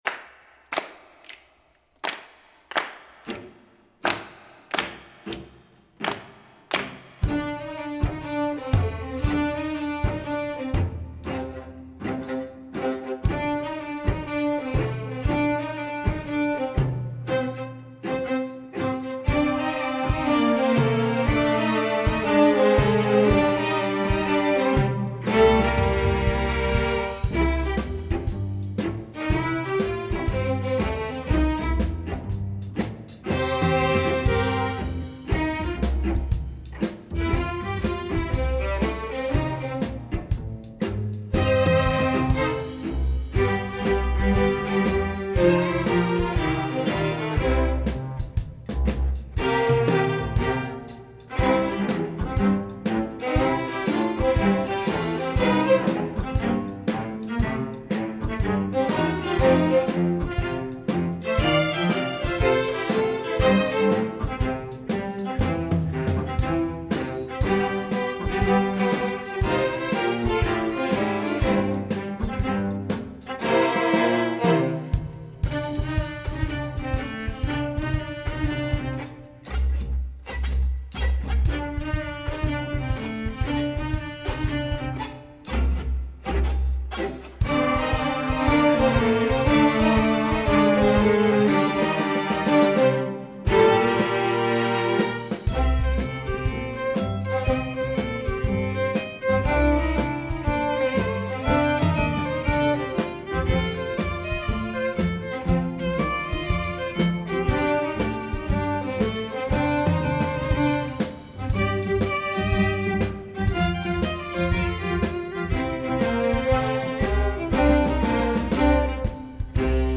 Instrumentation: string orchestra
1st violin part:
Viola part:
Cello part:
String bass part:
Drums part: